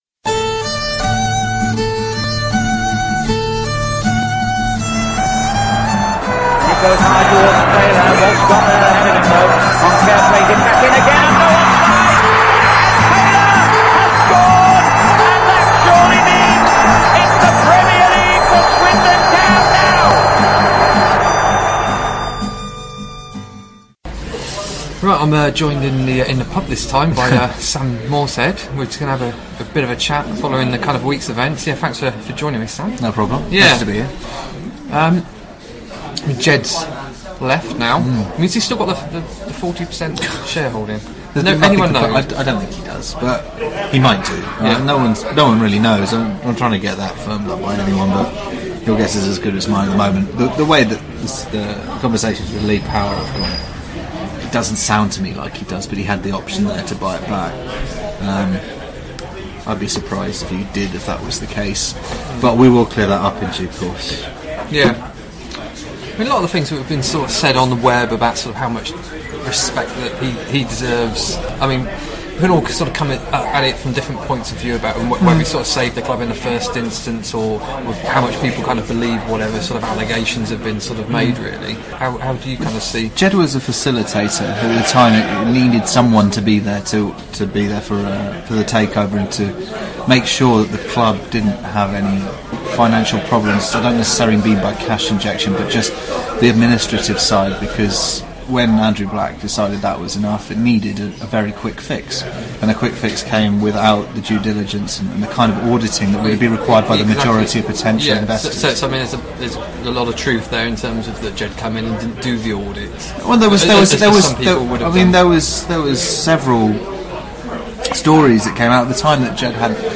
Unfortunately the sound quality isn’t that great so hopefully you can follow the discussion amongst the background chatter and noise of Christmas parties…